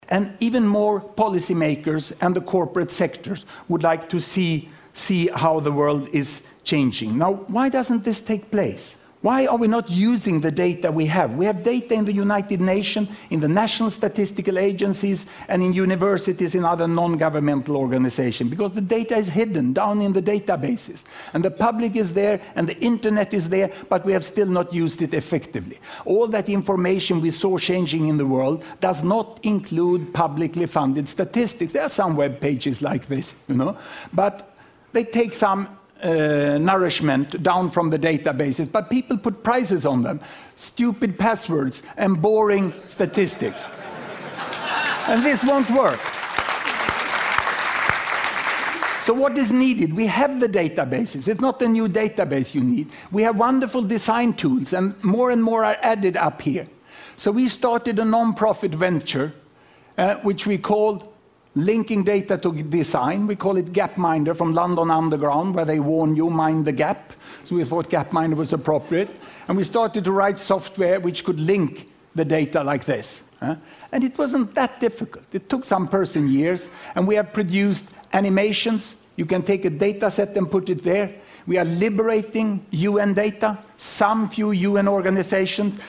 TED演讲：用前所未有的好方法诠释数字统计(9) 听力文件下载—在线英语听力室